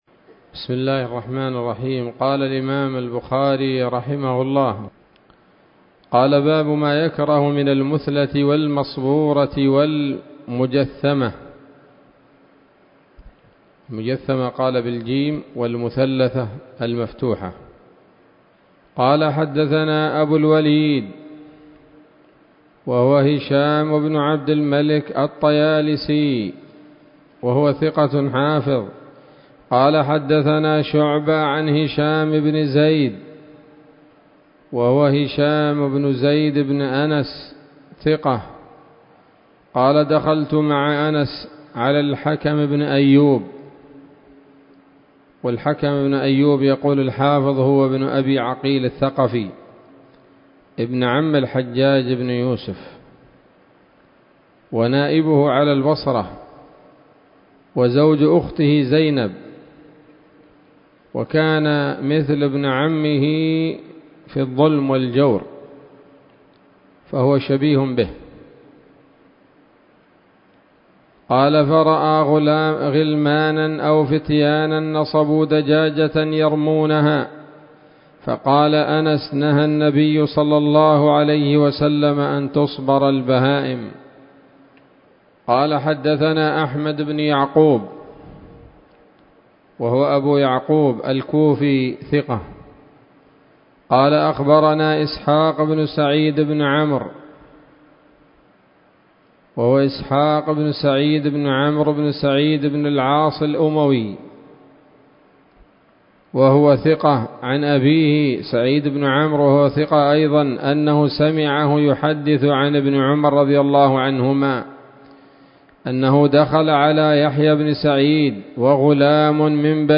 الدرس الحادي والعشرون من كتاب الذبائح والصيد من صحيح الإمام البخاري